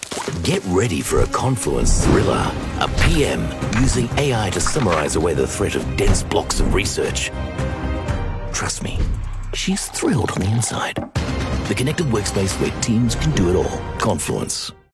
Dynamic, approachable, friendly and natural Australian VoiceOver
Television Spots
Tech Commercial-Bright-Fun